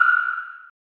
sonar2.mp3